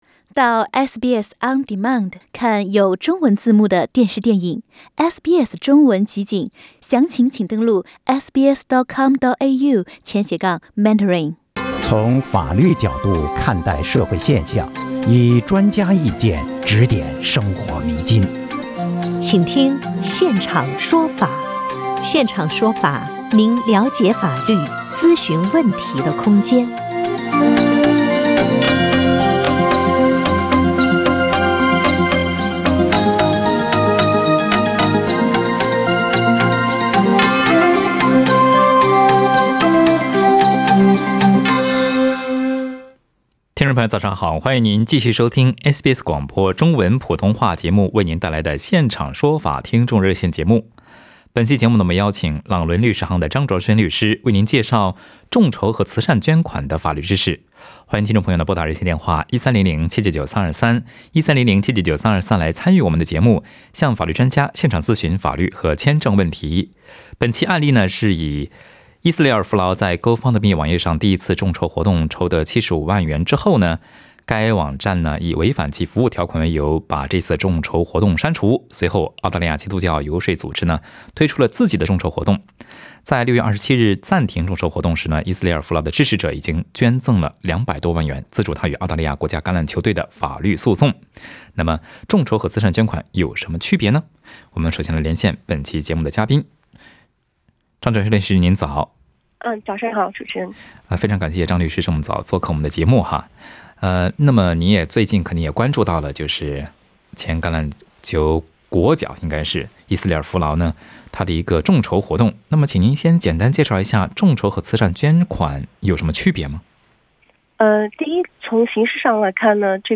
在本期节目中，还有听众咨询了离婚后国际房产以及财产分割，自己申请配偶移民的材料准备等法律和移民签证问题。